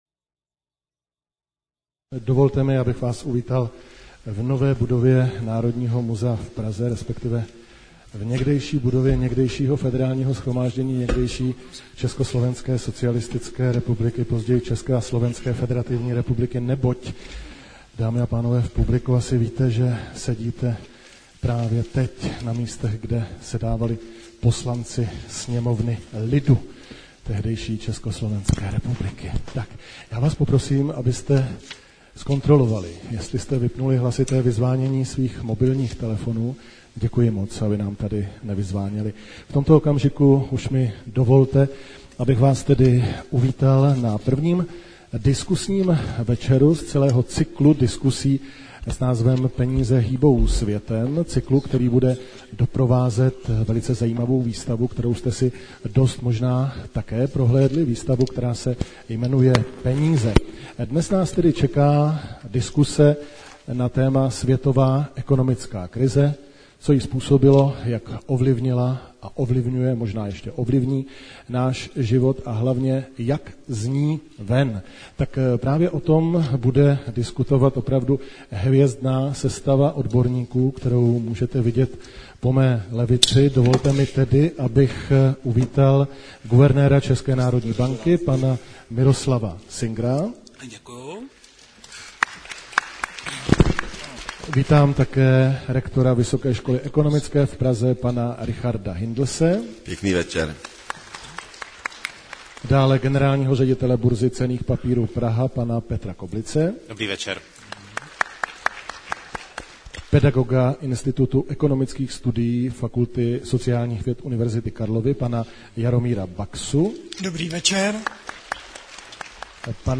záznam debaty |